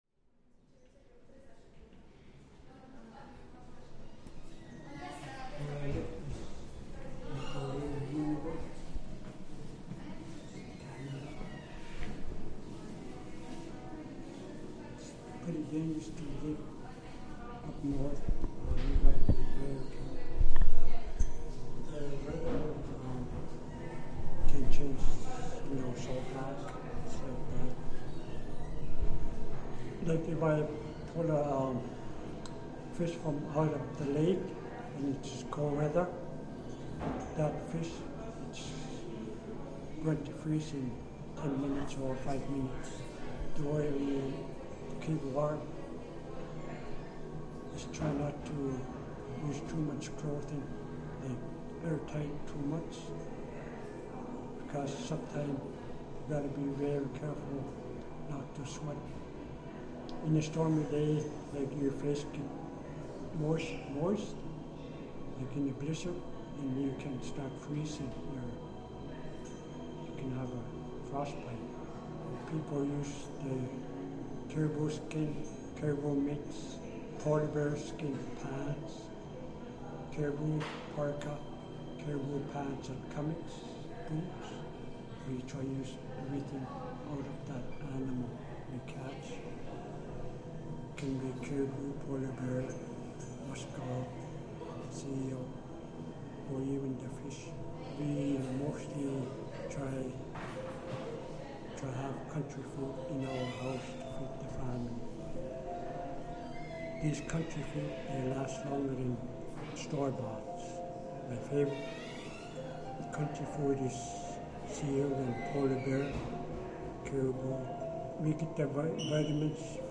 Inside the National Maritime Museum in Maritime Greenwich, we explore the extensive polar exhibition, in which sound plays a major part. In this recording we hear a description of some of the traditional ways of life of the Inuits, and later, a verbal description of life as a polar researcher. These video installations bring to life the maritime world at the heart of the wider heritage site.